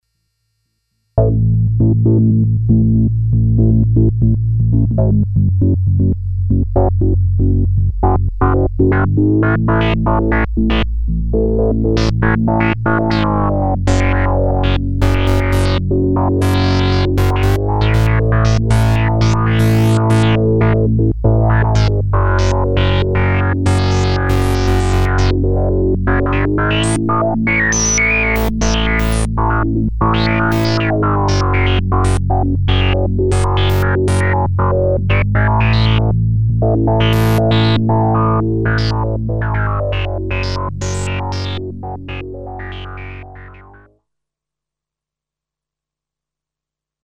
Random - preset S&H Filter (no.27)
Glide,tweaking Cutoff and MOD.VCF, played from keyboard.